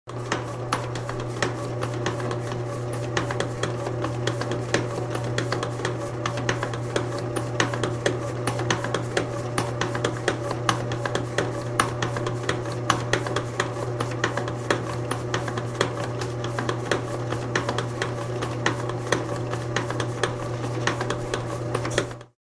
На этой странице собраны звуки хлебопечки — от мерного гула двигателя до сигнала готовности хлеба.
Шепот выпекающегося хлеба